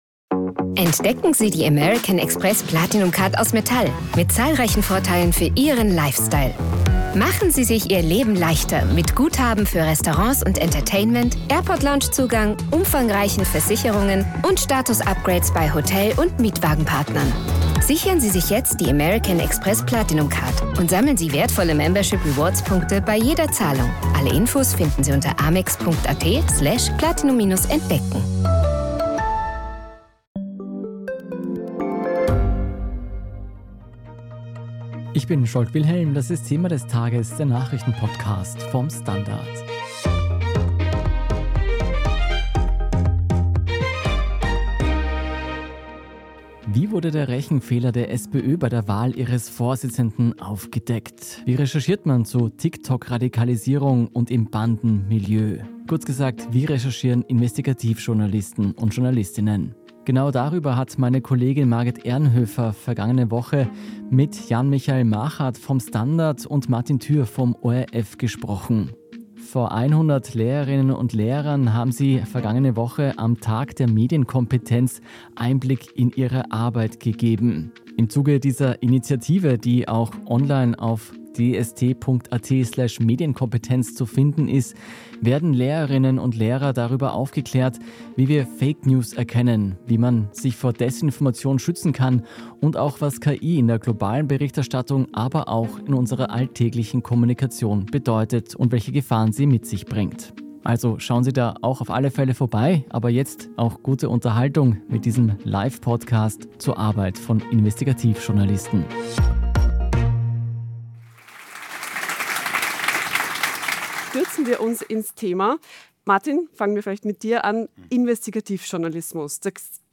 Die Folge wurde live im ORF-Zentrum aufgezeichnet.